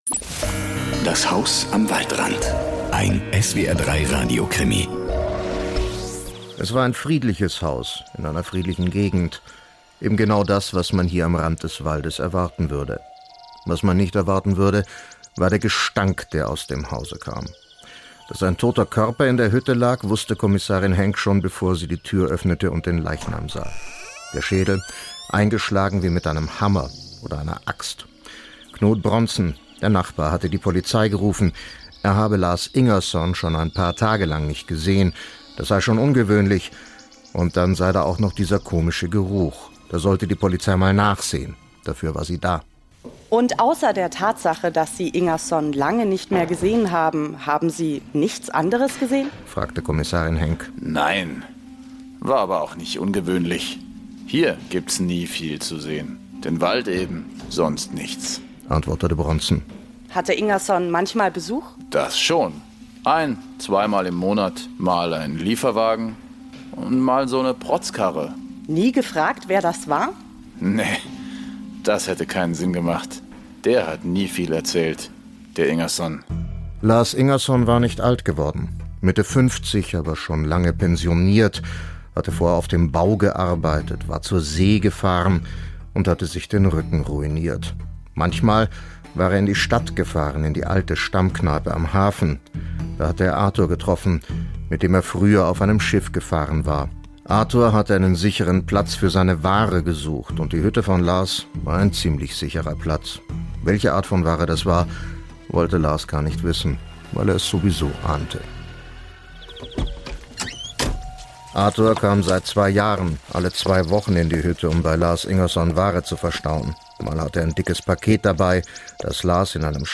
Der SWR3-Radiokrimi zum Miträtseln am Dienstag, dem 15.1.2019.